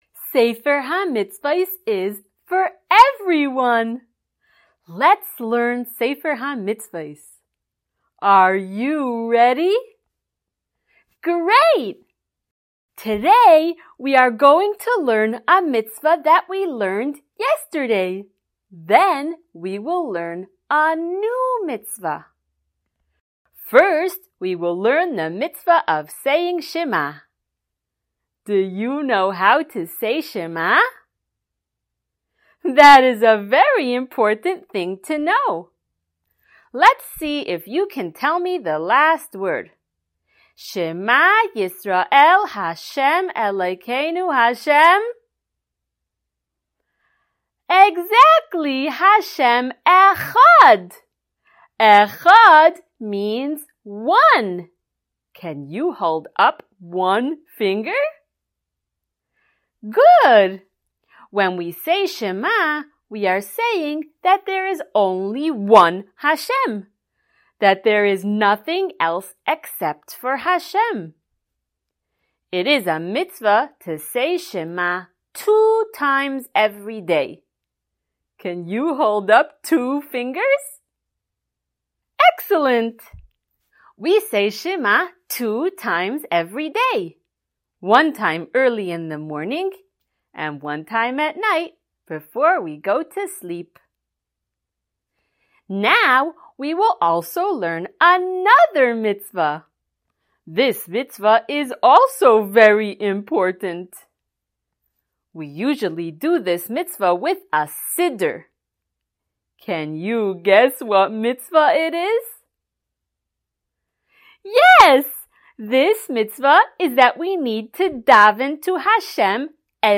SmallChildren_Shiur021.mp3